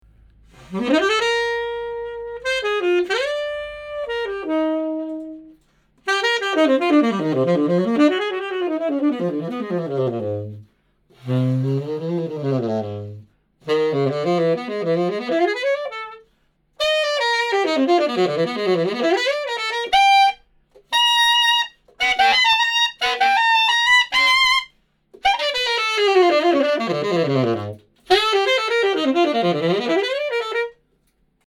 3D Binaural Recording of Gottsu Mouthpieces
We have binaurally recorded each Gottsu mouthpiece in order to give you an accurate representation of what it sounds like.